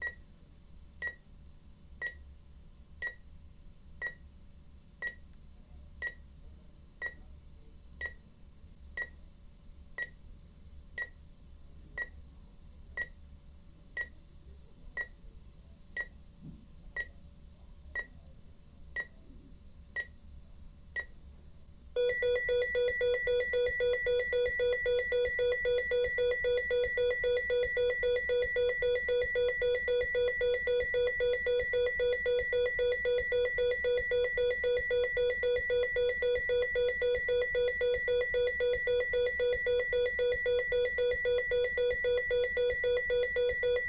heart-monitor-sound